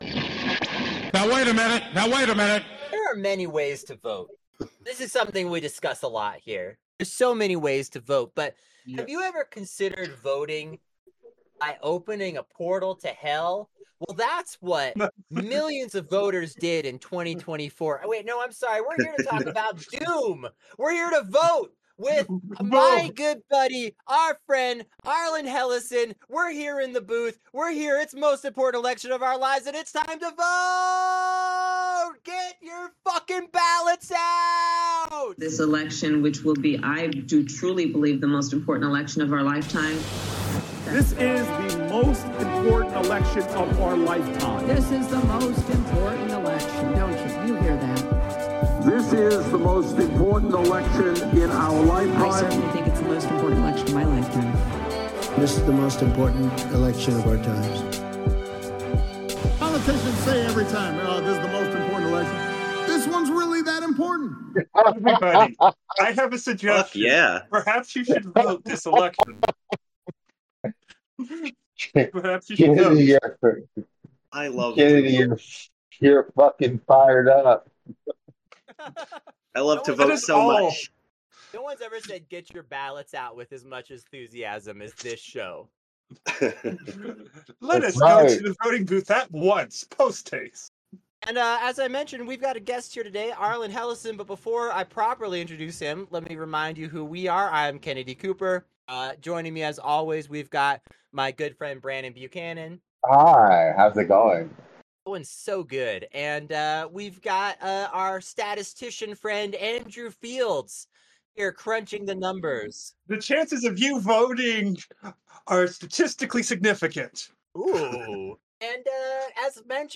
1 Andrew Huberman: OnlyFans Is Hijacking Your Brain - Fake Intimacy, Real Consequences PT 2 1:14:05 Play Pause 1h ago 1:14:05 Play Pause Play later Play later Lists Like Liked 1:14:05 In Part 2 of this explosive conversation, Tom Bilyeu continues his deep dive with Dr. Andrew Huberman, unraveling not just the biological and social traps ensnaring men, but the actionable steps required to break free. If Part 1 exposed the crisis, Part 2 provides a roadmap back to purpose, agency, and authentic accomplishment.